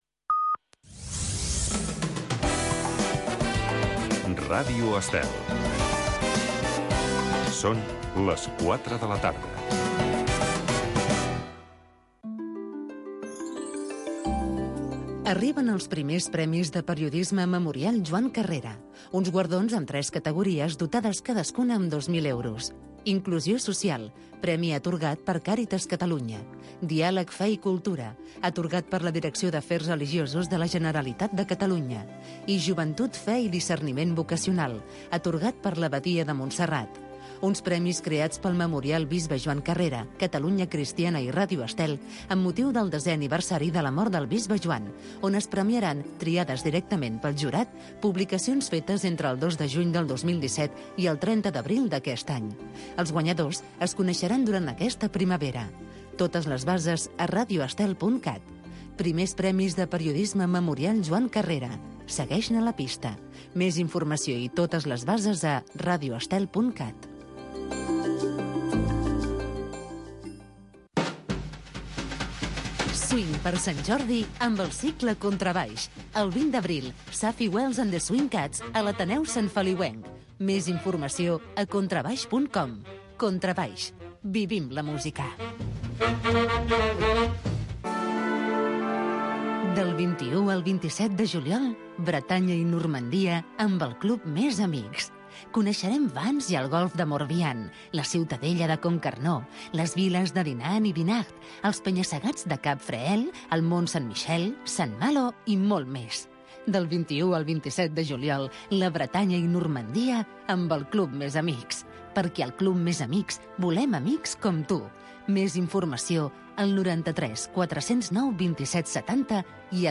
Fórmula musical de jazz